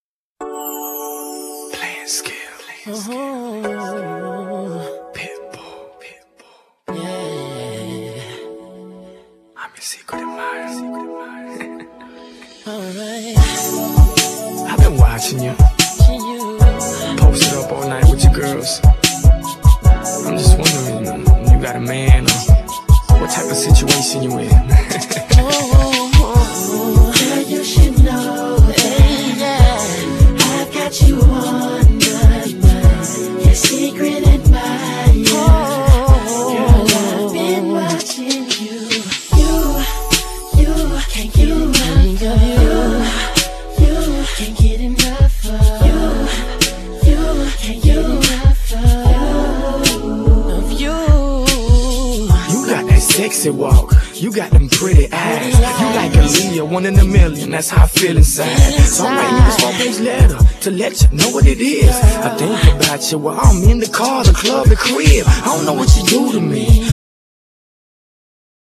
Genere : Latino rap